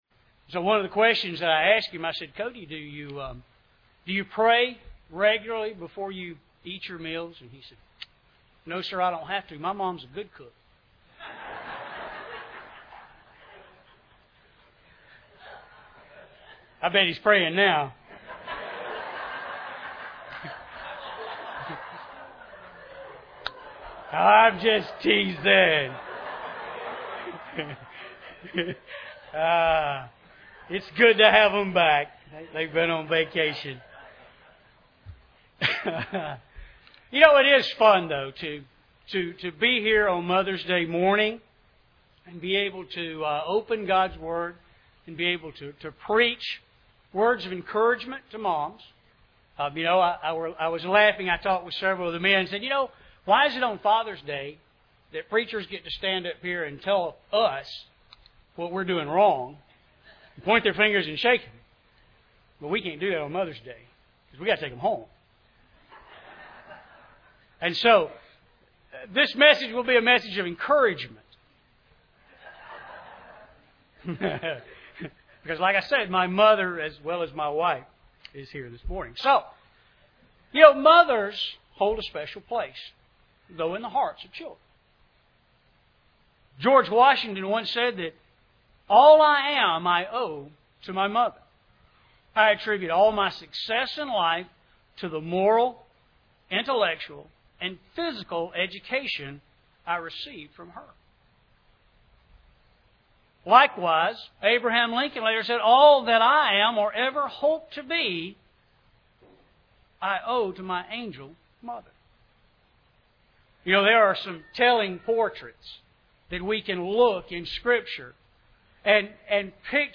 2 Timothy 1:5 Service Type: Sunday Morning Bible Text